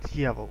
Ääntäminen
IPA: /ˈdʲjavəl/